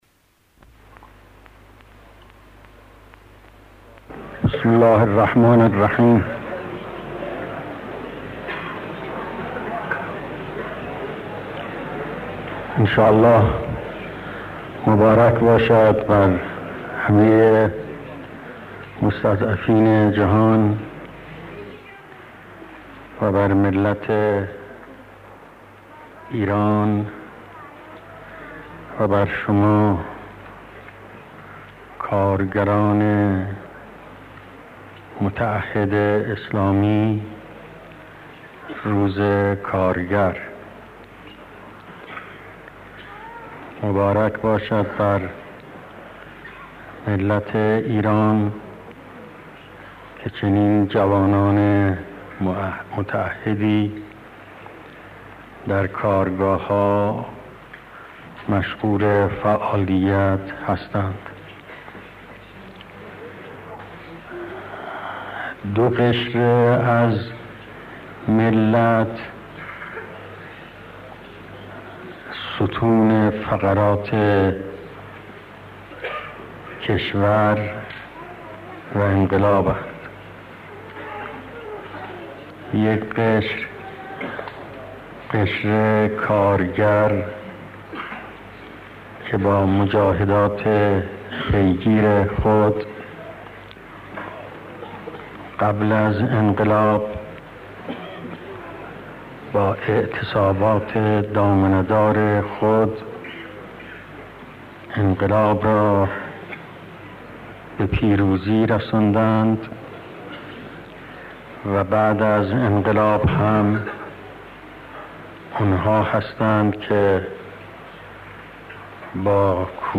اهمیت نقش کارگران و کشاورزان در استقلال کشور اهمیت نقش کارگران و کشاورزان در استقلال کشور ۰:۰۰ ۰:۰۰ دانلود صوت کیفیت بالا عنوان : اهمیت نقش کارگران و کشاورزان در استقلال کشور مکان : تهران، حسینیه جماران تاریخ : ۱۳۶۰-۰۲-۱۰